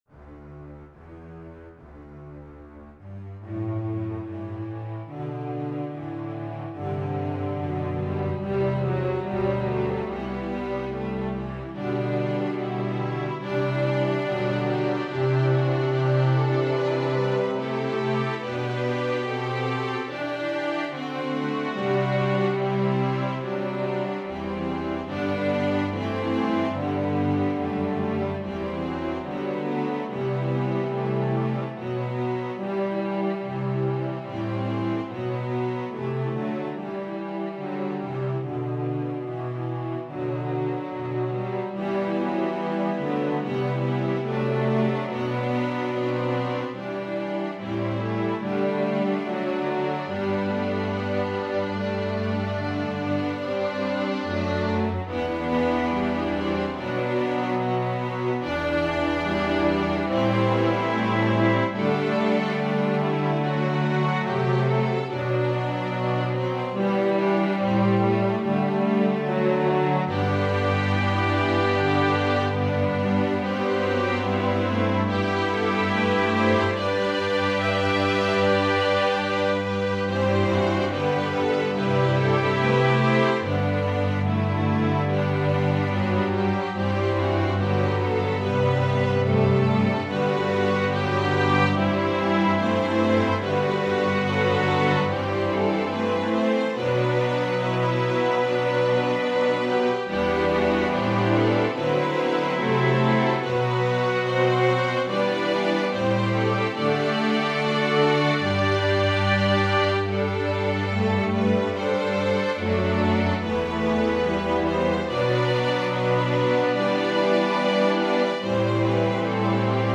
Anyway, I am using horns as my voice replacement.